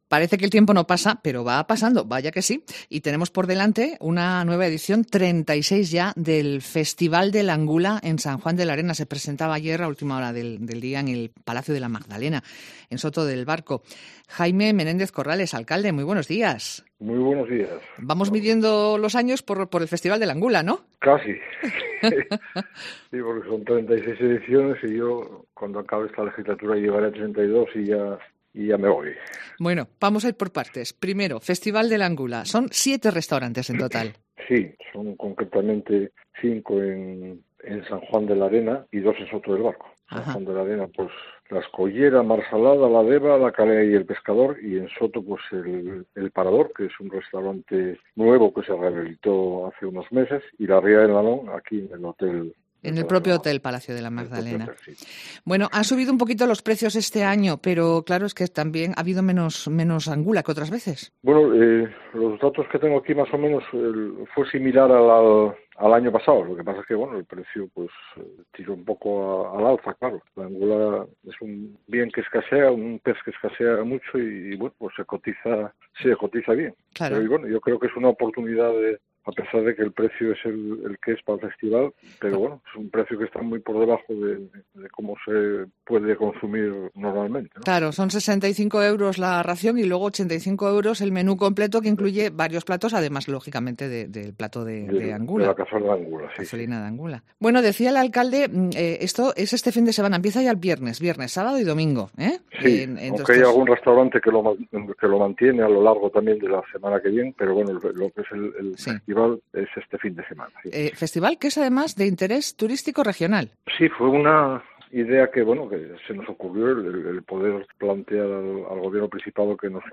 Entrevista con Jaime Menéndez Corrales